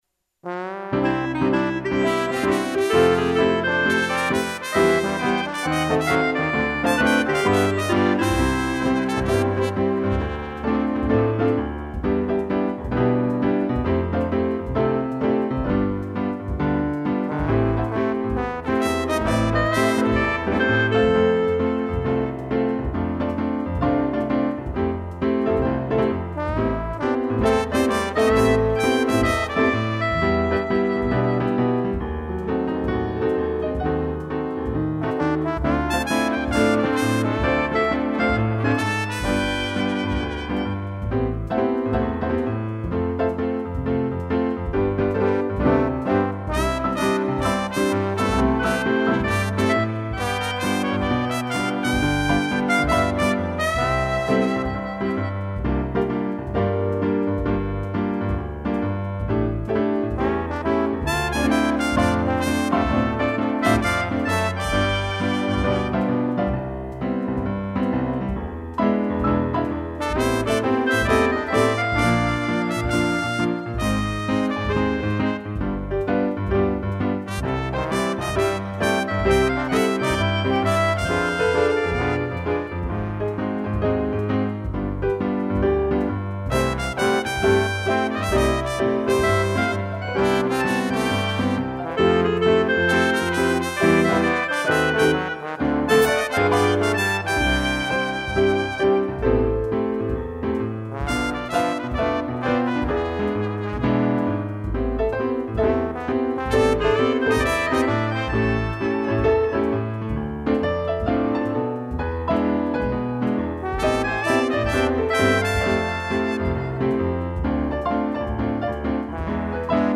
piano, trombone, trompete e clarinete
(instrumental)